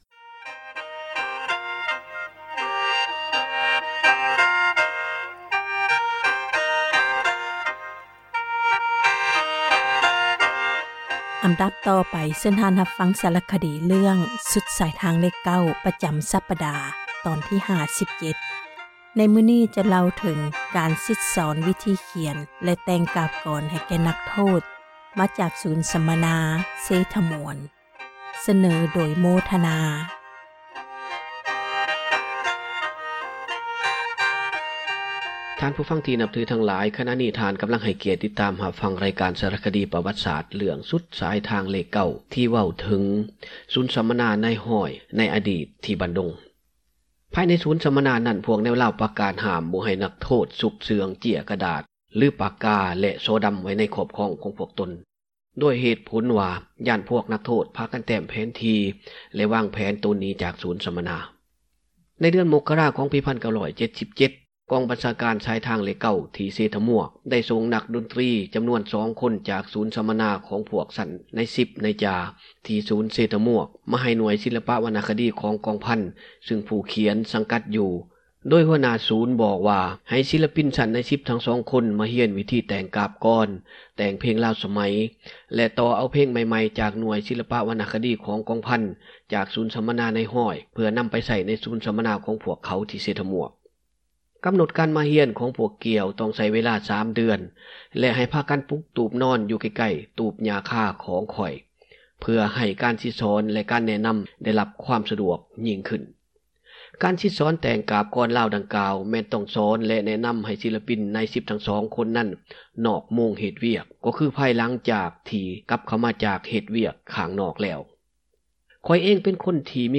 ສາຣະຄະດີ ເຣື້ອງ ສຸດສາຍທາງເລຂ 9 ຕອນທີ 57 ຈະເລົ່າເຖິງ ການສິດສອນ ວິທີຂຽນ ແລະ ແຕ່ງກາບກອນ ໃຫ້ແກ່ນັກໂທດ ມາຈາກ ສູນ ສັມມະນາ ເຊທ່າມວກ.